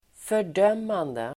Uttal: [för_d'öm:ande]